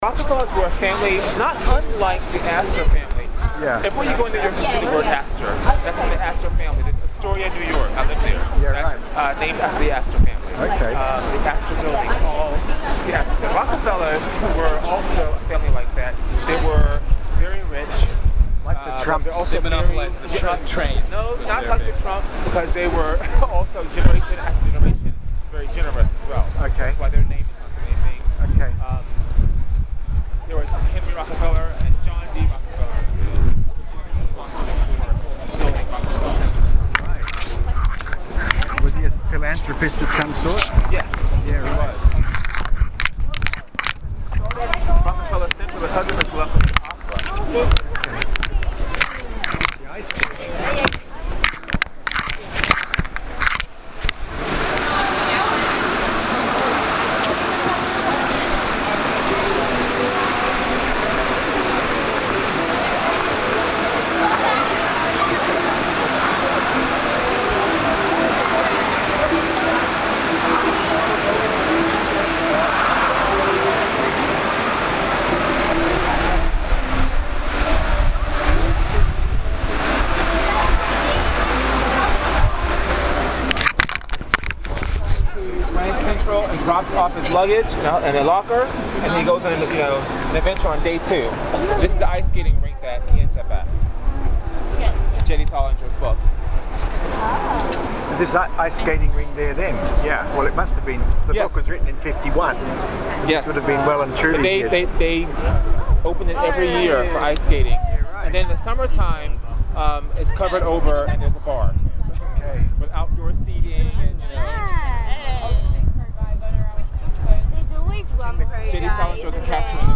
Here is our guide on the Rockefellers: